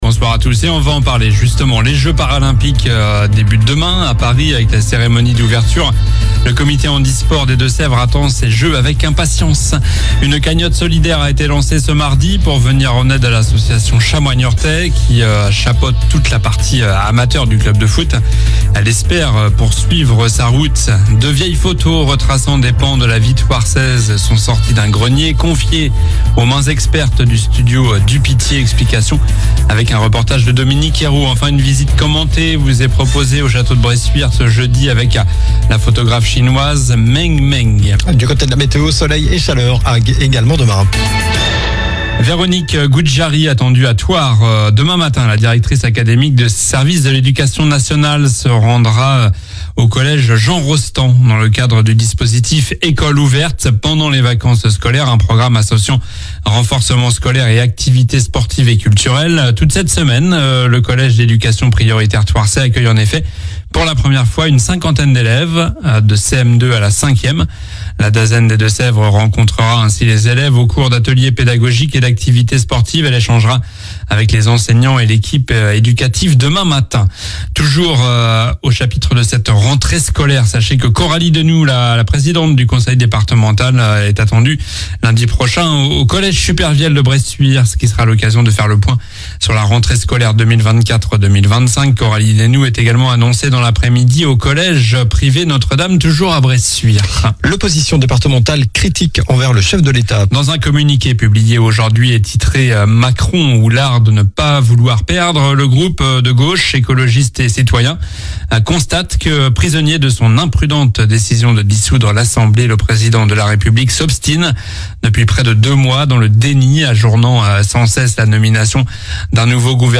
Journal du mardi 27 août (soir)